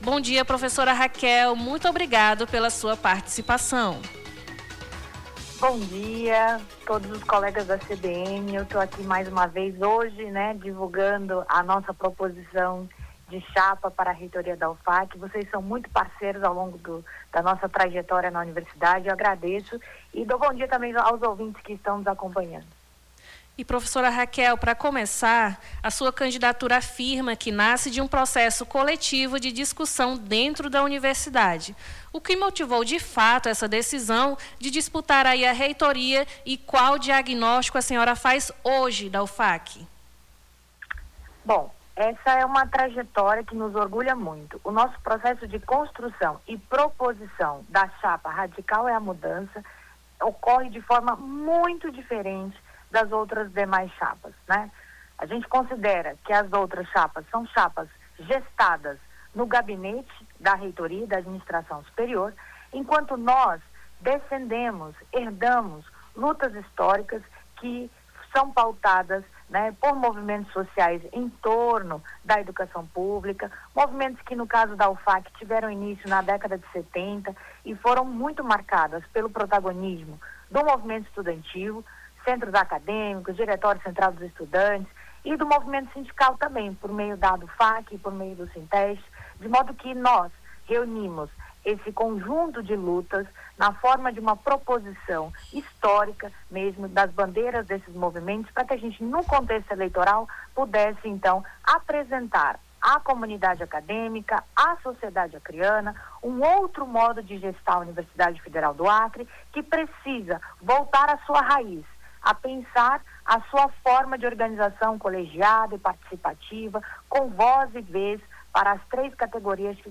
ENTREVISTA CANDIDATA REITORA UFAC